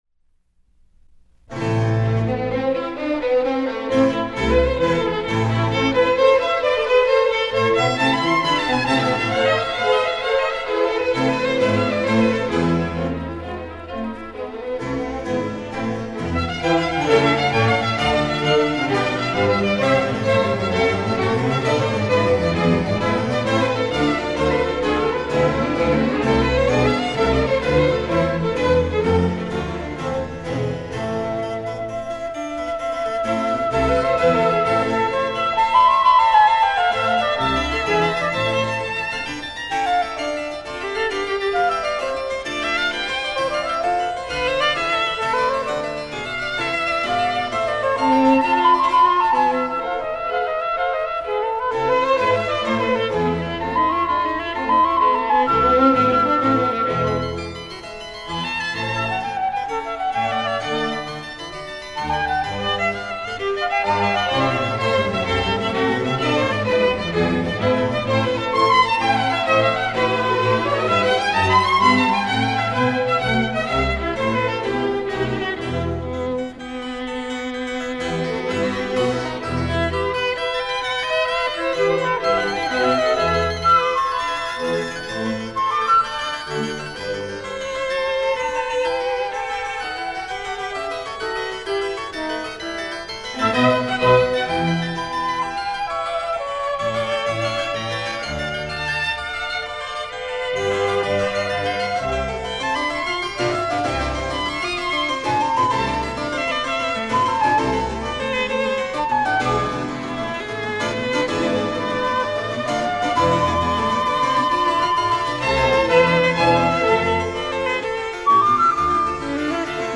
clavier, flûte et violon
Triolets, notes pointées et pizzicatos propulsent ce mouvement long vers l’avant. Le deuxième mouvement est une transcription du mouvement central de la Sonate en trio en ré mineur, BWV 527 pour orgue.
Des explosions d’accords absolument féroces fusent de l’orchestre comme pour condamner les pécheurs.